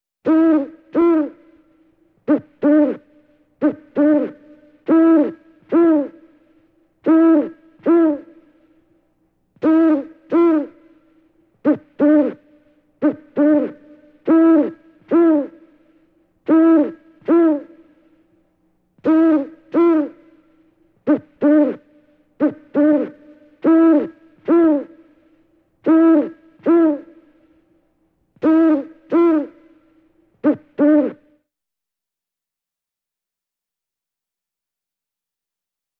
Звуки филина
На этой странице собраны звуки филина — мощные крики, уханье и другие голосовые проявления этой величественной птицы.